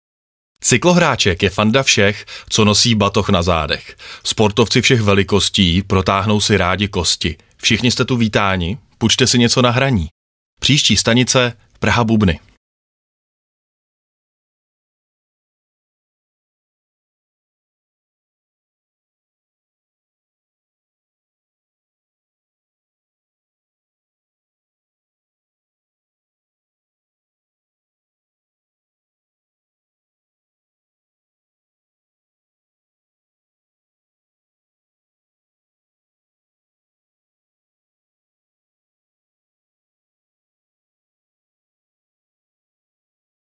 Na trase Beroun - Praha tak cestující nejen, že neměli problém dostat se do práce a z práce, ale ještě si mohli užít příjemného prostředí a za poslechu vtipných hlášení stanic jako například: